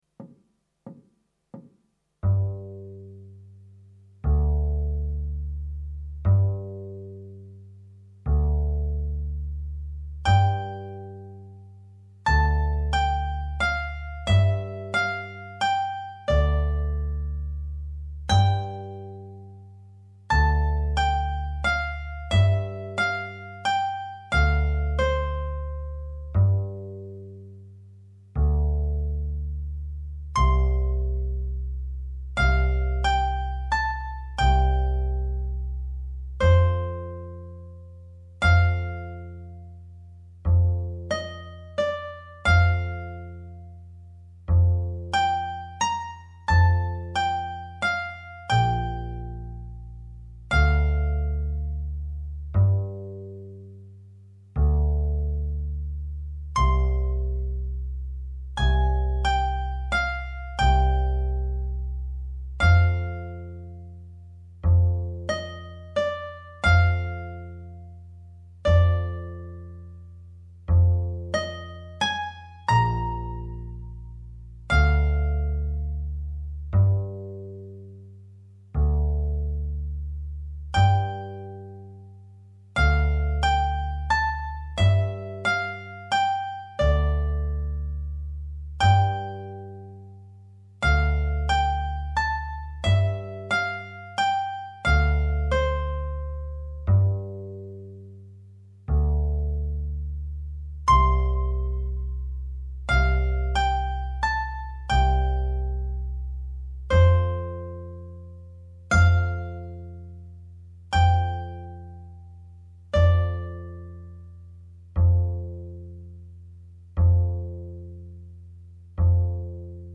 minus Guitar 2